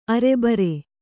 ♪ arebare